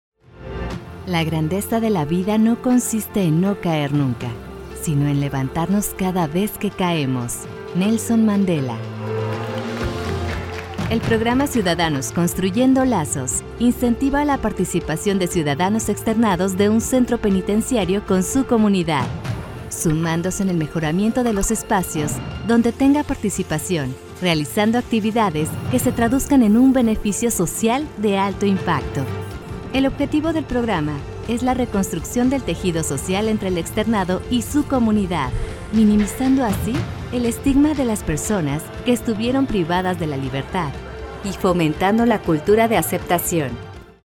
Commercial, Natural, Cool, Versatile, Corporate
Corporate